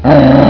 growl2.wav